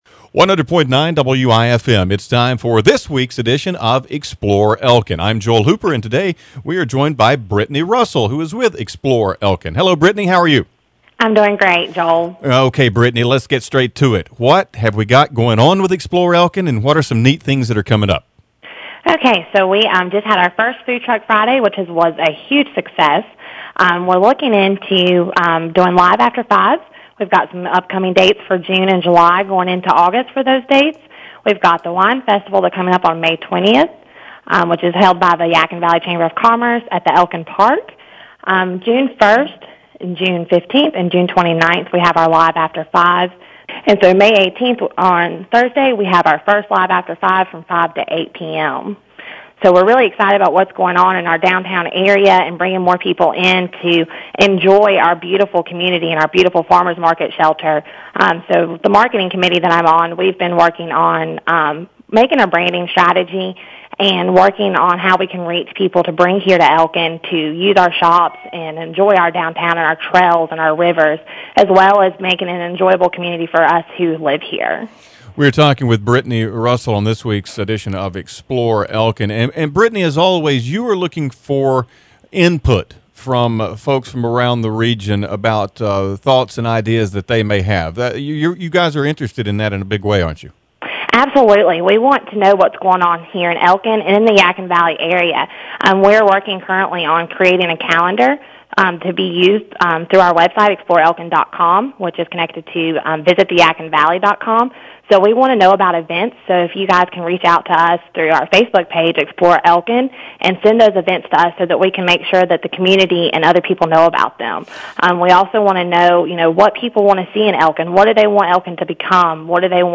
Weekly updates from Explore Elkin are broadcast on Tuesday afternoon at 2:05 pm on 100.9 WIFM.